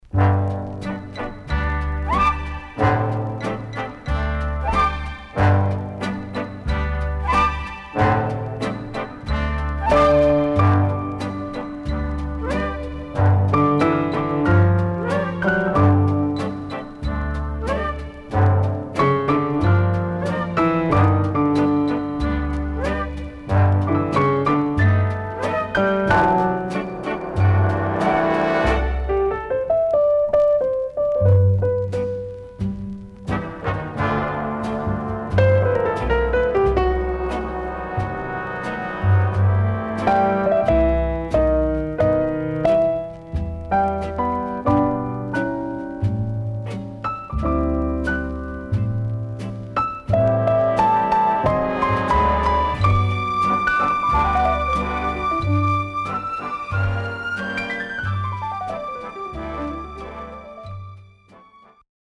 HOME > SOUL / OTHERS
SIDE A:少しチリノイズ入ります。